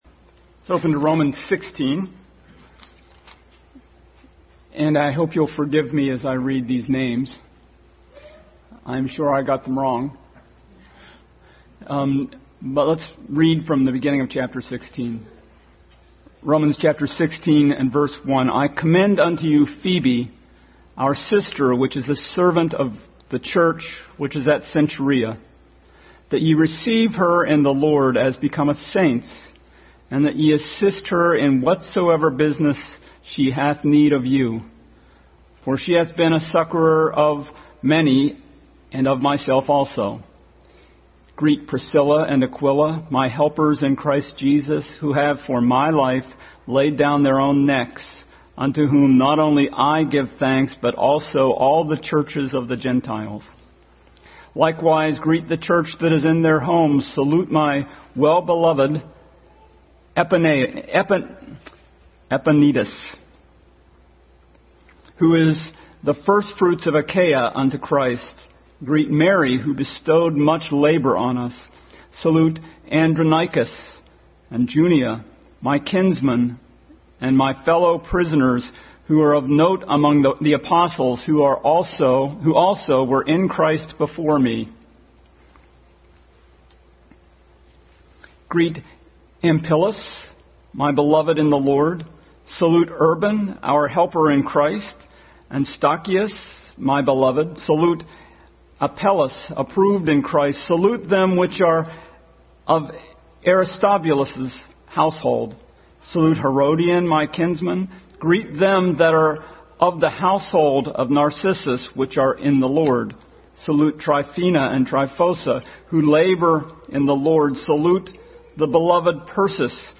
Romans 16:1-20 Passage: Romans 16:1-20 Service Type: Family Bible Hour Family Bible Hour Message.